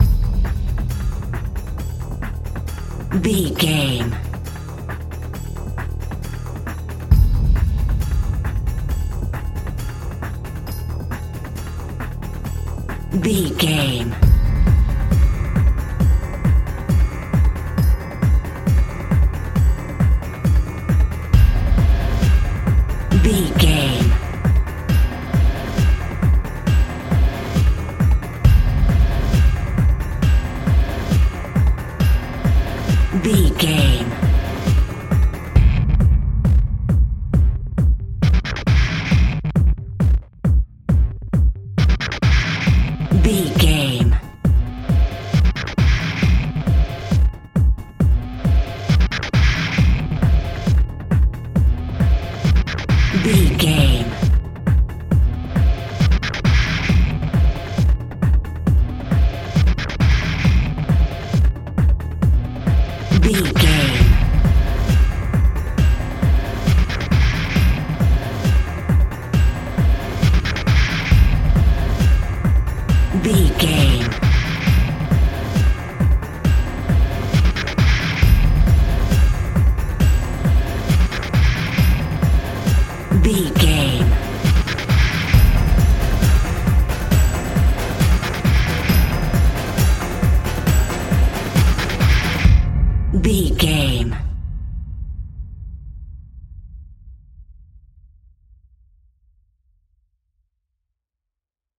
Aeolian/Minor
Fast
tension
ominous
dark
eerie
driving
synthesiser
drums
drum machine